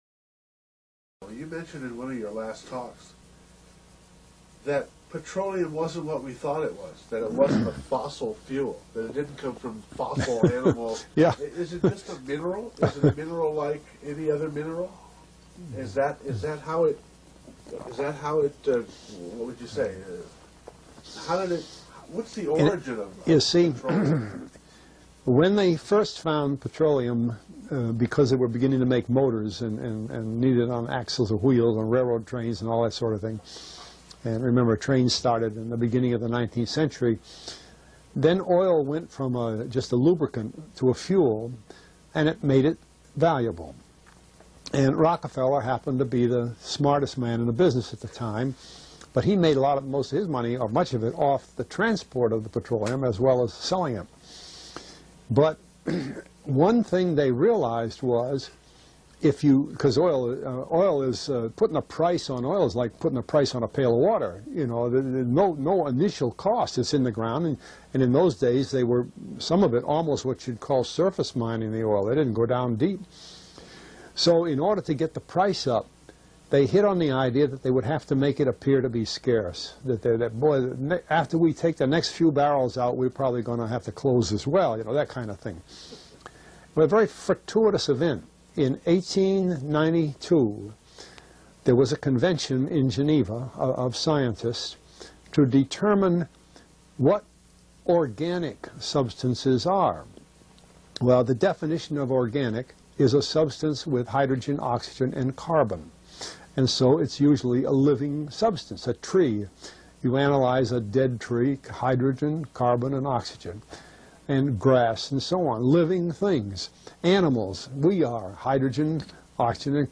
Re: Interview